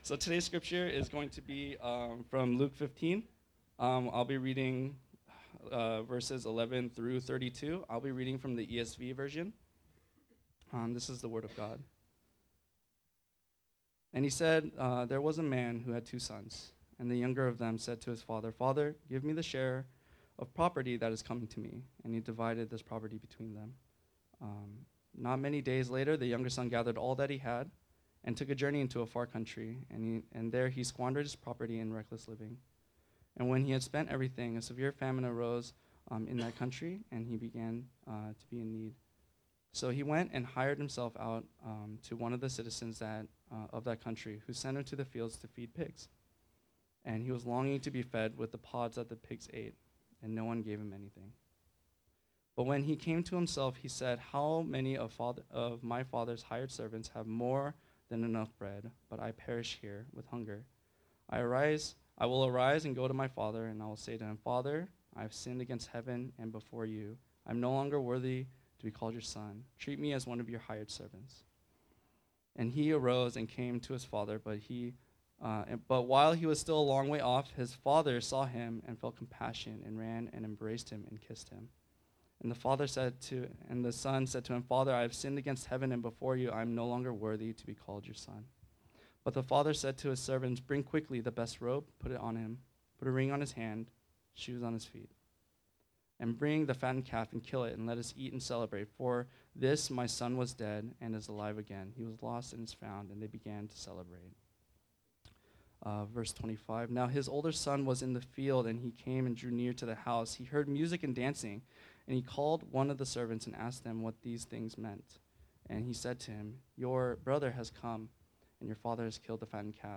Archived Sermons | Kairos Church